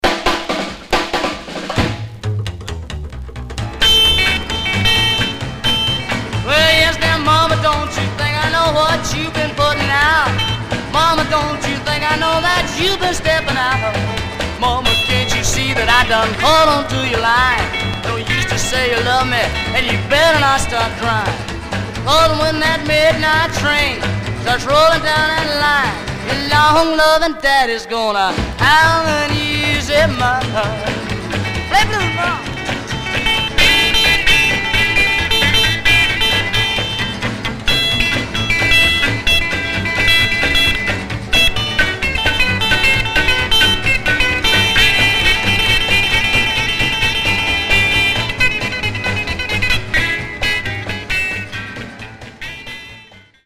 Stereo/mono Mono
Rockabilly